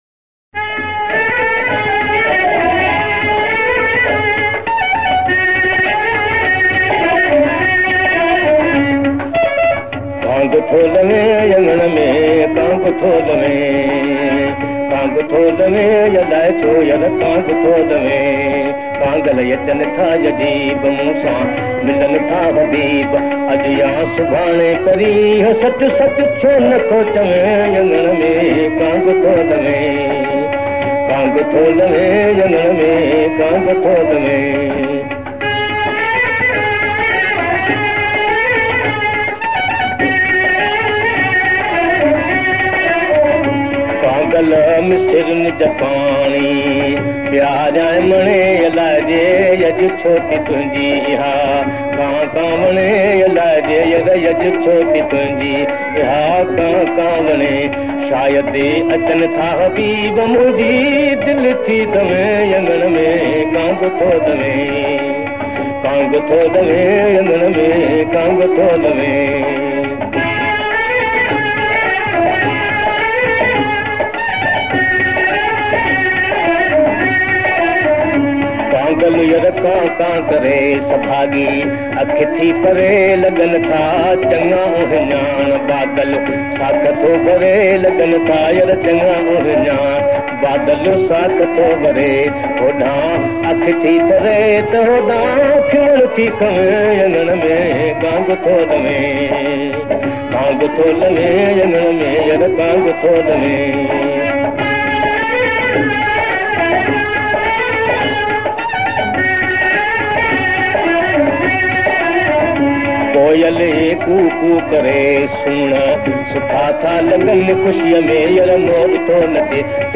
Classic Sindhi Songs